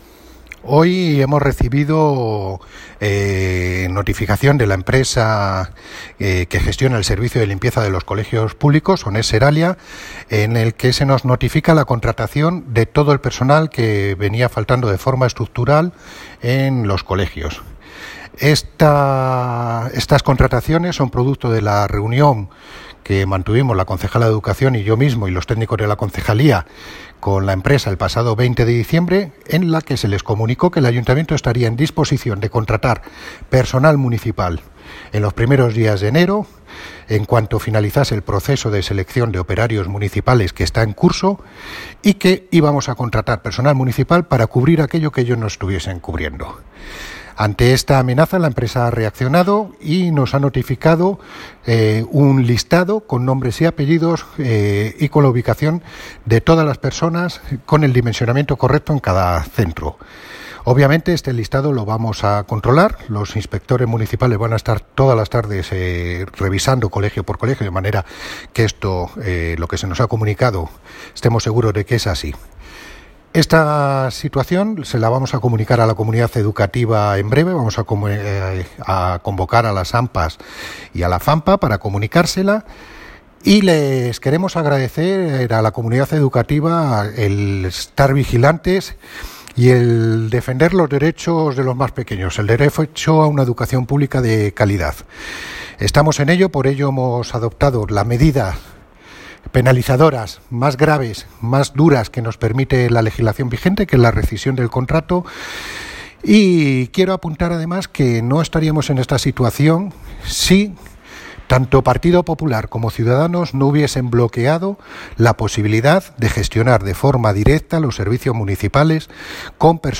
Audio - Miguel Angel Ortega (Concejal de Medio Ambiente, Parques, Jardines y Limpieza Viaria)